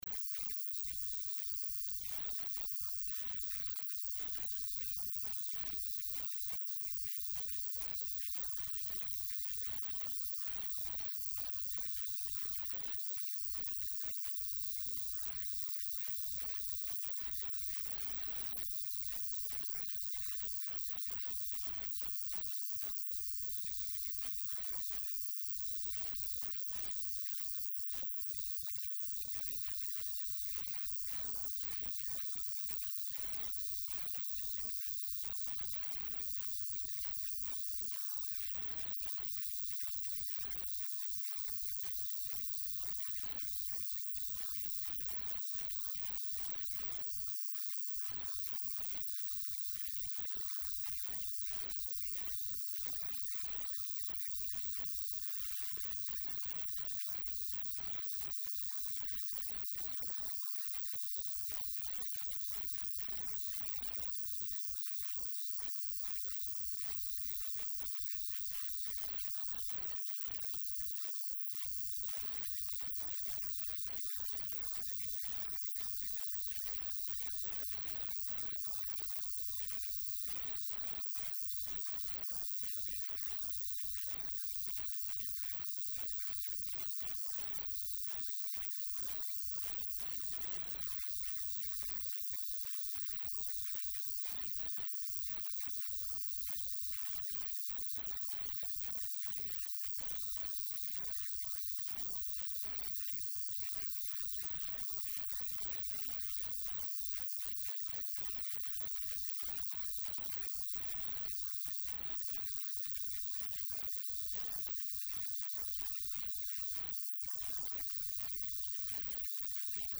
Wasiir Maxmed C/laahi Salaad Omaar Wasaaaradda Gaadiidka iyo Duulista Hawada Xukumadda Federaalka Soomaaliya ayaa wareysigaan siiyay Laanta Afka Soomaaliga ee VOA taasoo bahwadaag la ah Radio Muqdisho Codka Jahmuuriyadda Soomaaliya.